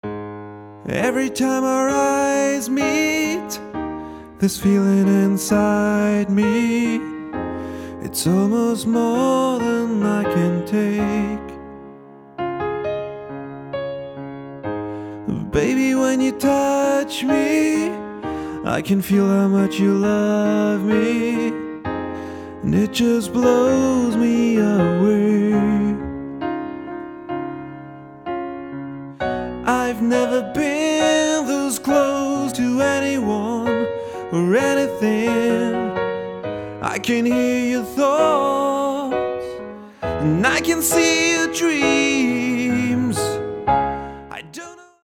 Duo oder Trio